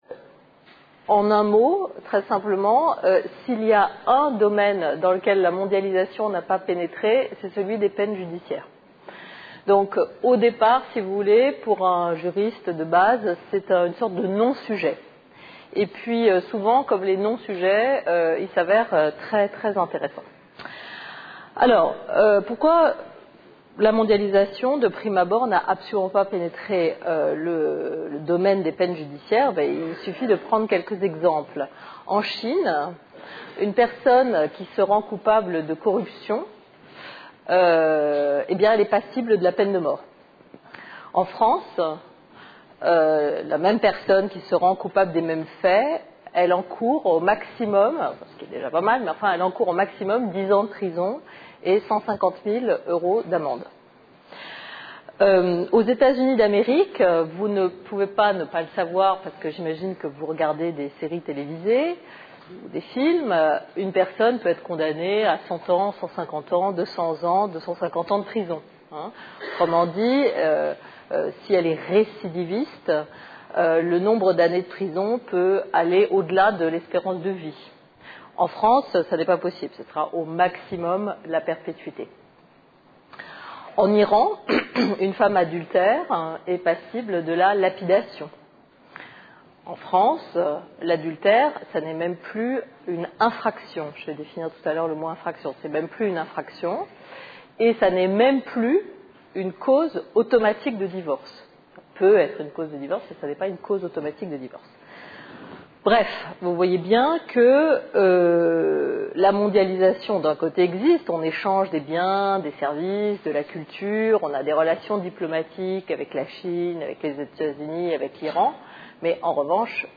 Une conférence de l'UTLS au Lycée A l'ère de la mondialisation, les peines judiciaires sont-elles identiques dans toutes les sociétés ?